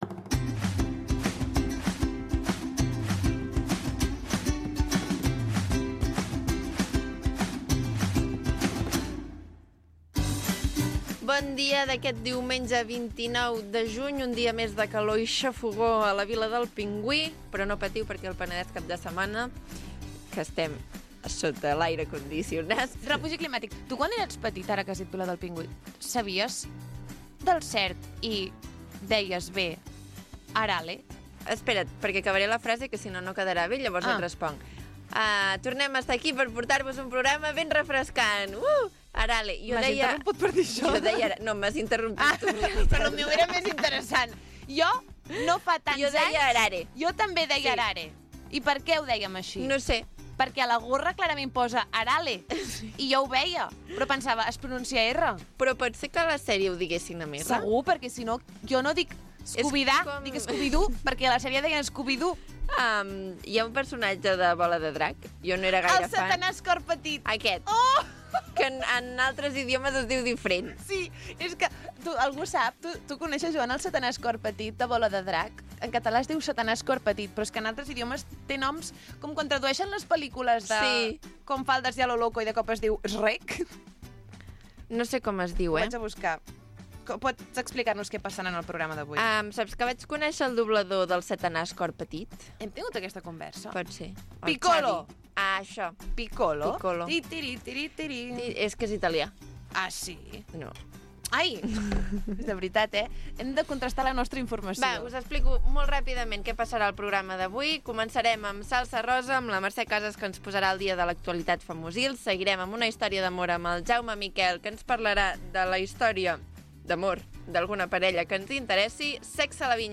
El magazín del cap de setmana